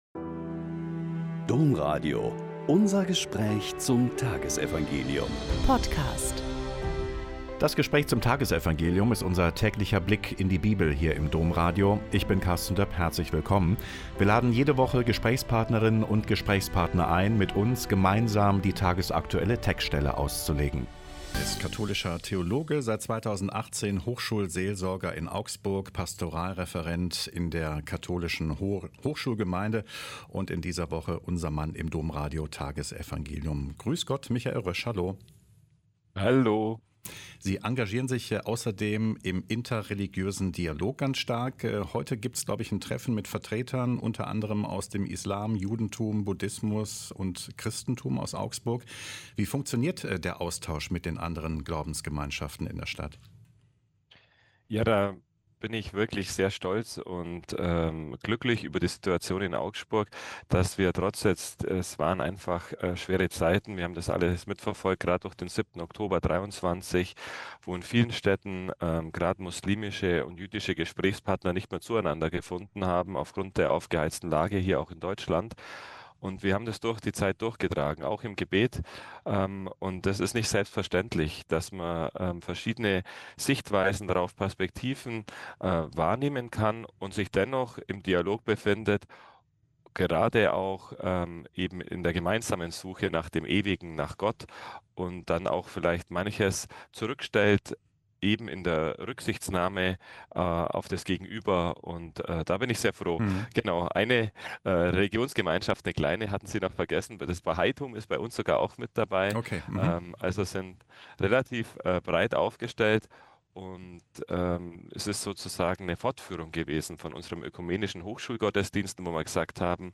Lk 11,47-54 - Gespräch